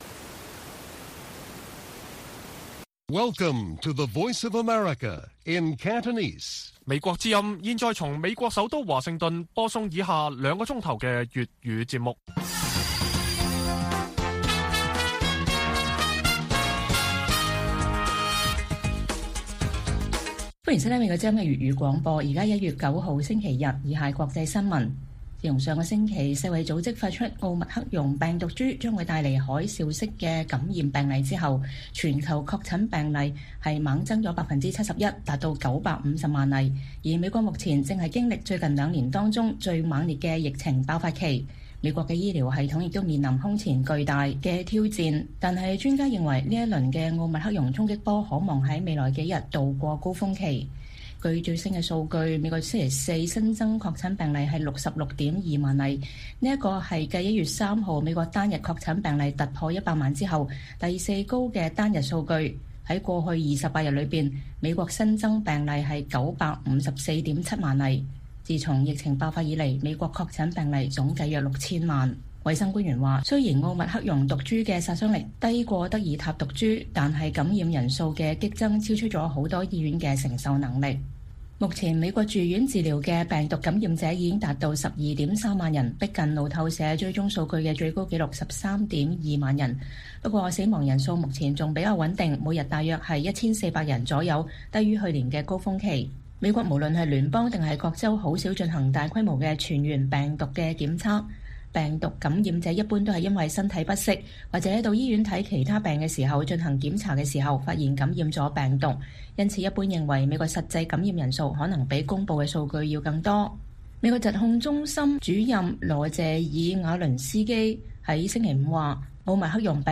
粵語新聞 晚上9-10點：專家分析變異毒株可能來如“海嘯”去時匆匆